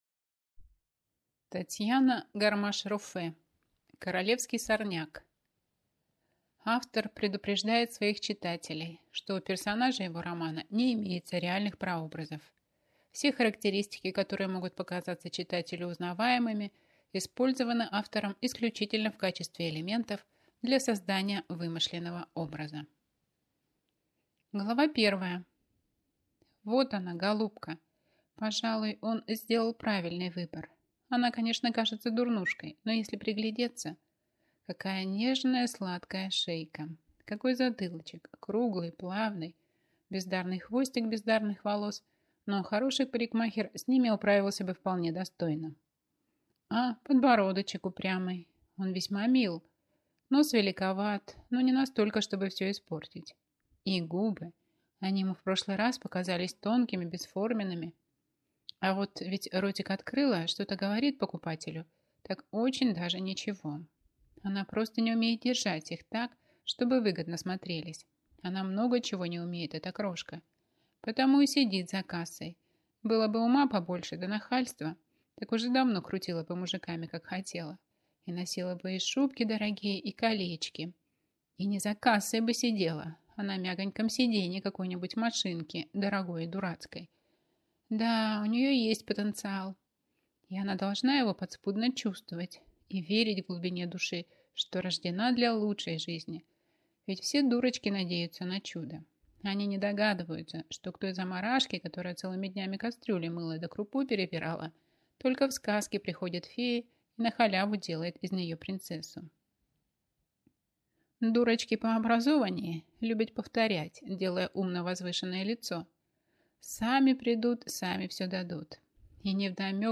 Аудиокнига Королевский сорняк - купить, скачать и слушать онлайн | КнигоПоиск